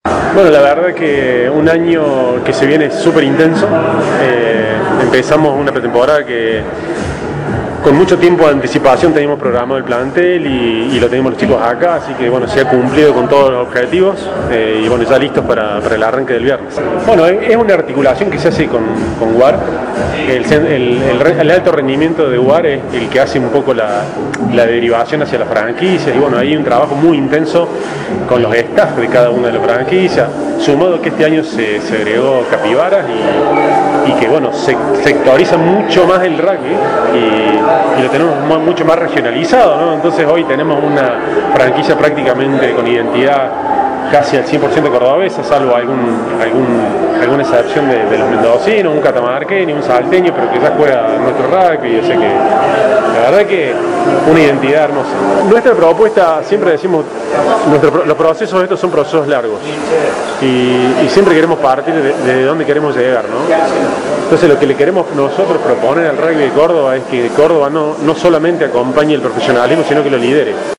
en la presentación de los DOGOS XV en el Polo Deportivo Kempes